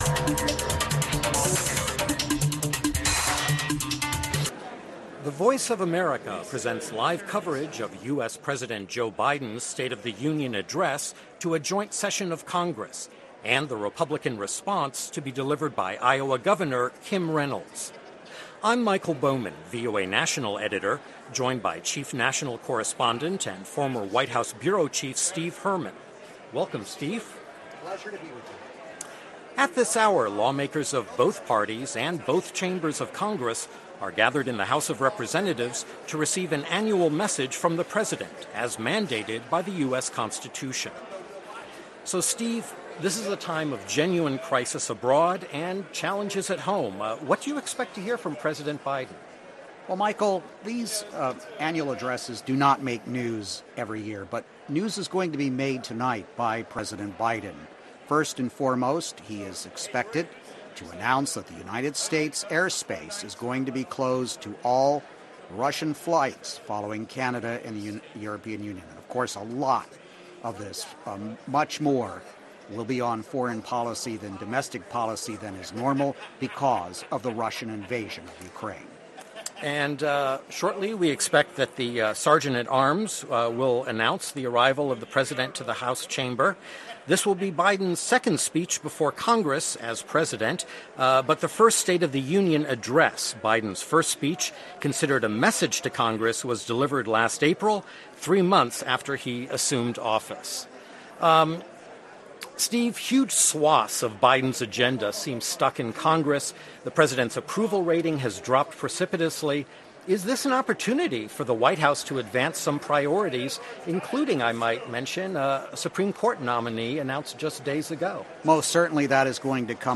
President Biden delivers his first State of the Union address amid an escalating conflict in Ukraine, the ongoing COVID-19 pandemic, and the highest rate of inflation that the United States has experienced in 40 years.